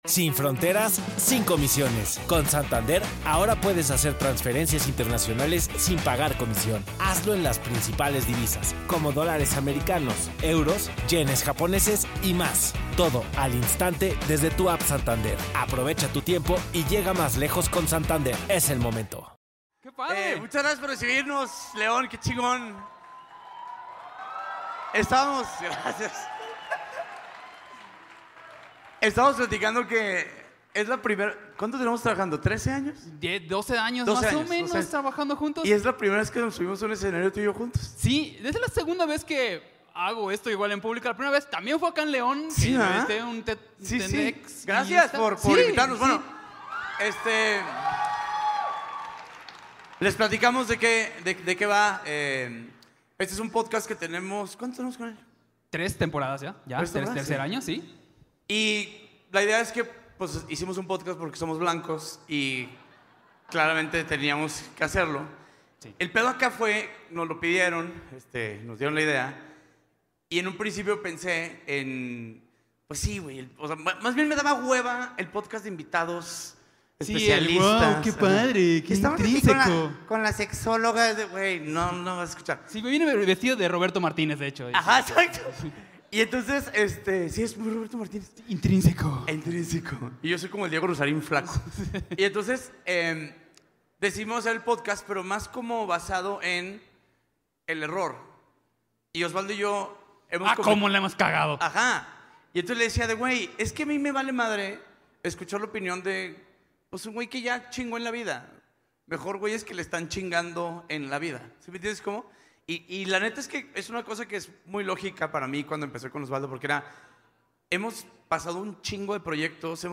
EP57 EN VIVO DESDE LEÓN
DÍA DE LA MENTEFACTURA - FESTIVAL DE LA INNOVACIÓN 2024